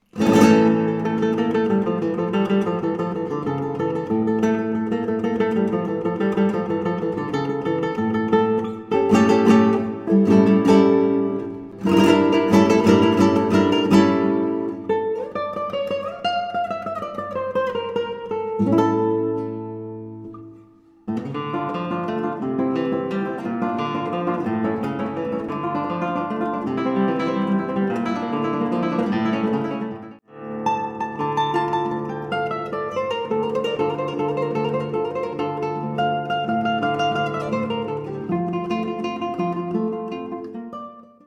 Solo Guitar and Guitar with String Orchestra